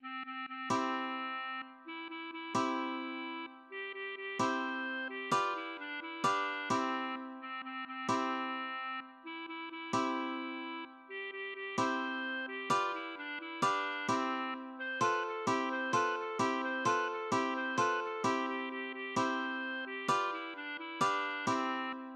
(gesungen anlässlich des Bardenwettbewerbs zur Krönung Großfürsts Alderan von Gareth zu Schloss Auenwacht)